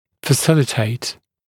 [fə’sɪlɪteɪt][фэ’силитэйт]облегчать, содействовать, способствовать
facilitate.mp3